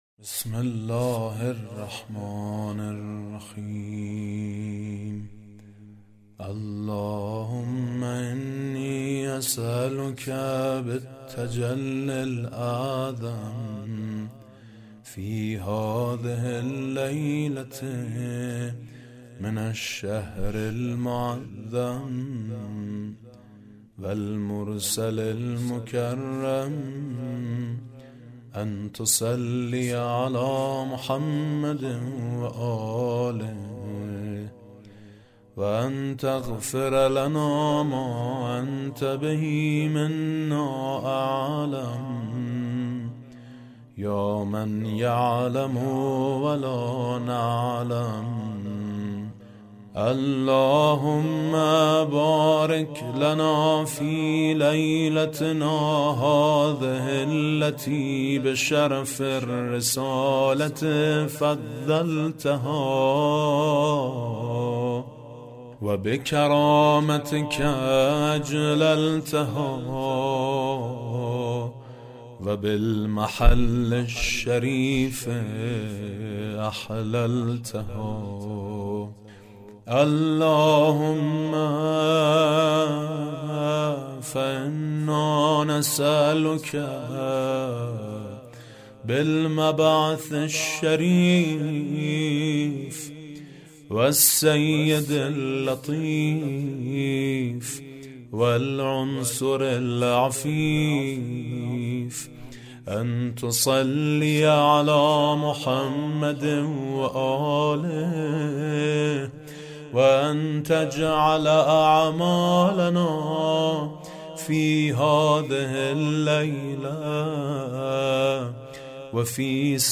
صوت/ "دعای شب مبعث" با نوای میثم مطیعی
دعای شب مبعث حضرت رسول (ص) را با صدای میثم مطیعی می شنوید.